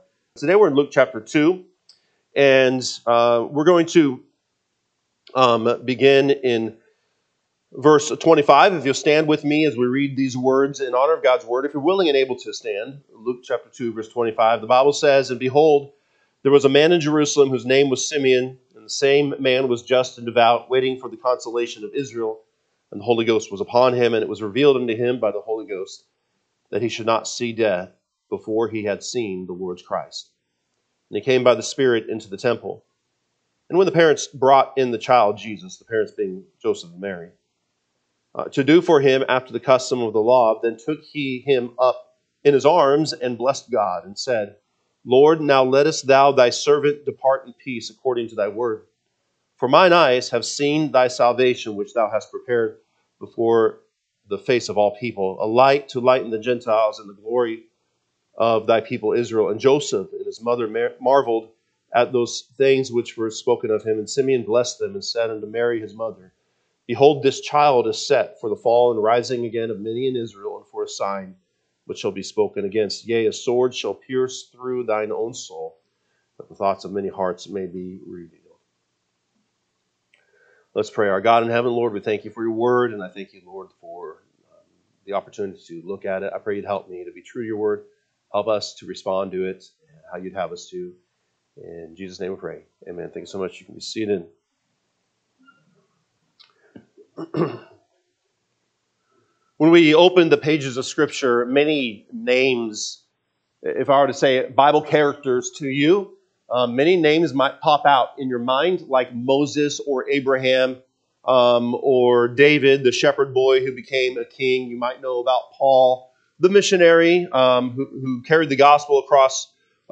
December 7, 2025 am Service Luke 2:25-35 (KJB) 25 And, behold, there was a man in Jerusalem, whose name was Simeon; and the same man was just and devout, waiting for the consolation of Israel:…